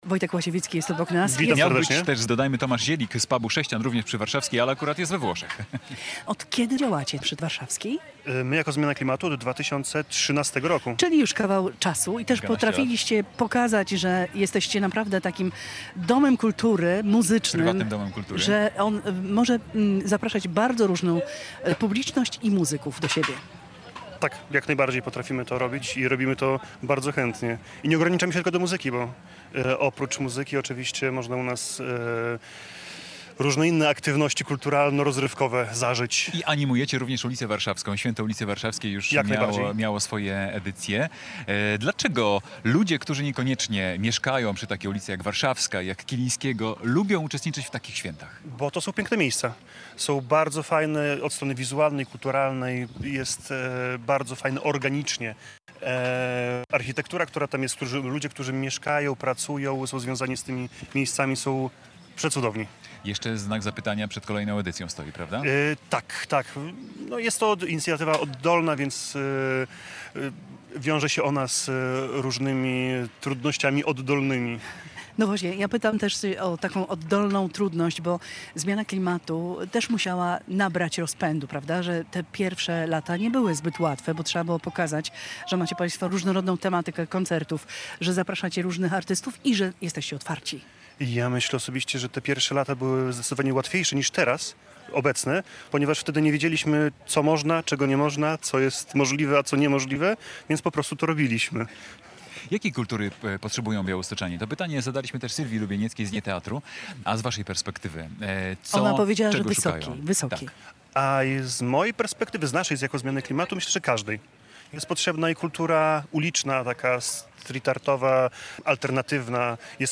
Polskie Radio Białystok w niedzielę otworzyło mobilne studio przy Ratuszu.